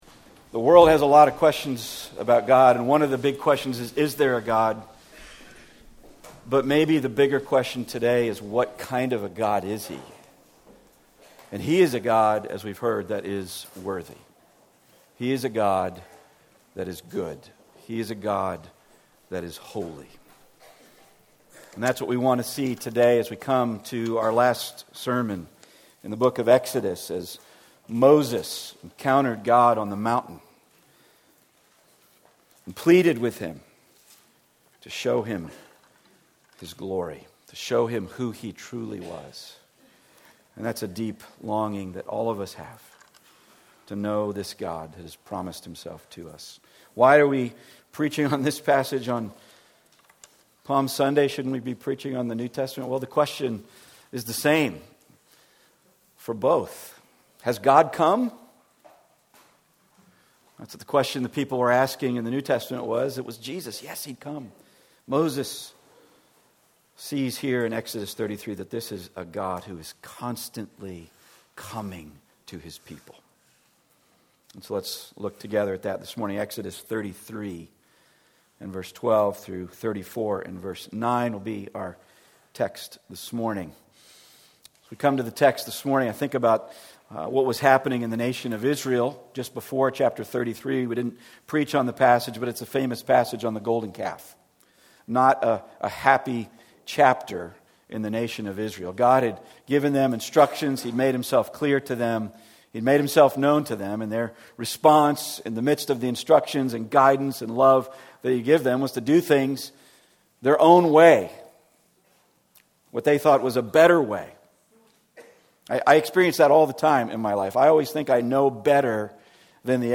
10 Commandments Passage: Exodus 33:12-34:9 Service Type: Weekly Sunday